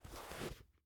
ES_Book Paperback 8 - SFX Producer.wav